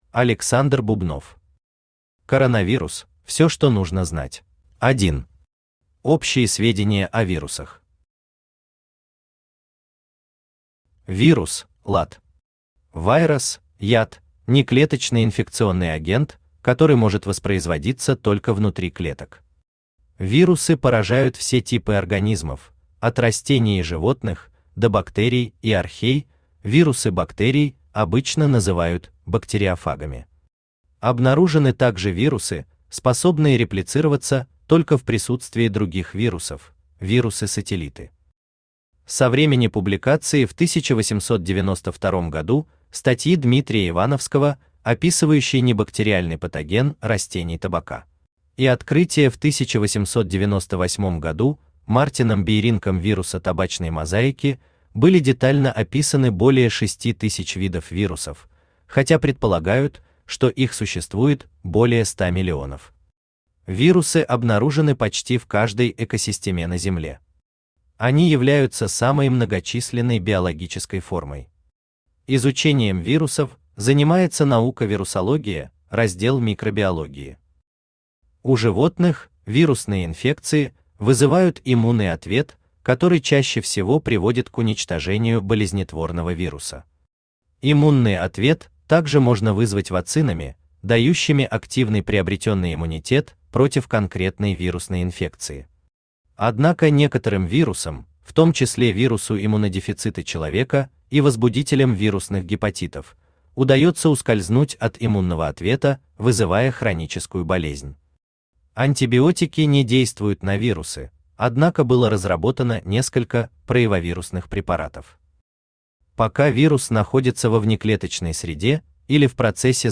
Aудиокнига Коронавирус: всё что нужно знать Автор Александр Иванович Бубнов Читает аудиокнигу Авточтец ЛитРес.